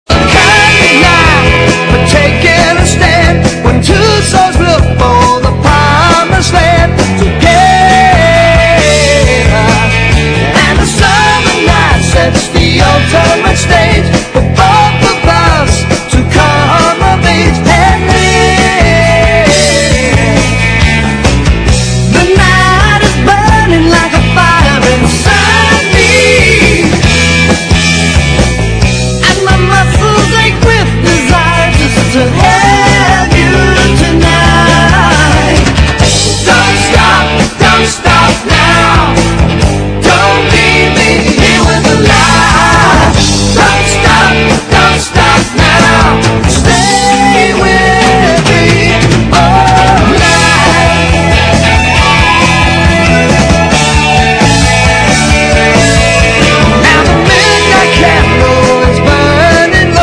ROCK / PUNK / 70'S/POWER POP/MOD / POWER POP
US POWER POP良質コンピ！